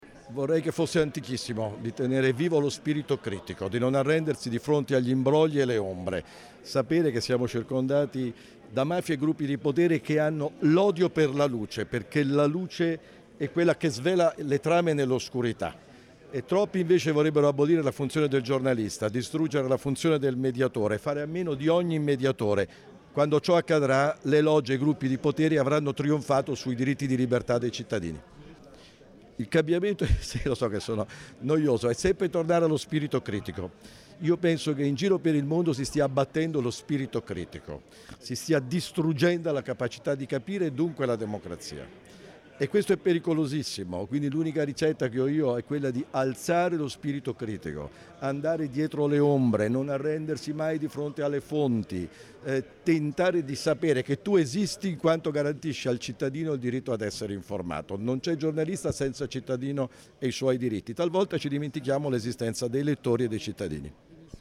A Trento Smart City Week il presidente della Federazione della Stampa, Giuseppe Giulietti
intervista_GIULIETTI_trento_smart_city.mp3